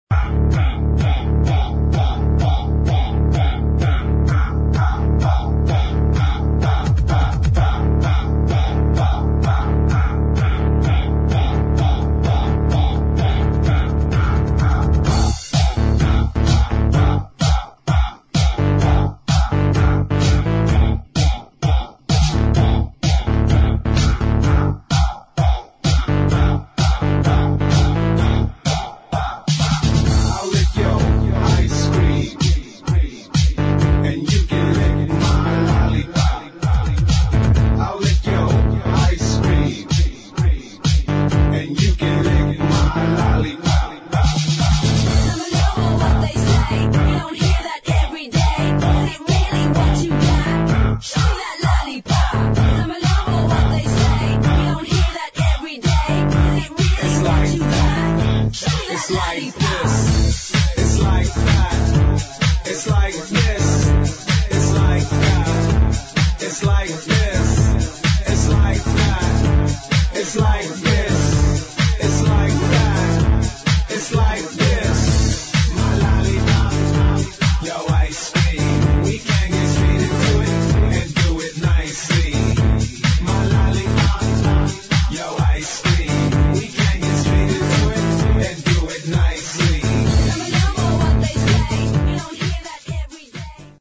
[ HOUSE / ELECTRO / ACID ]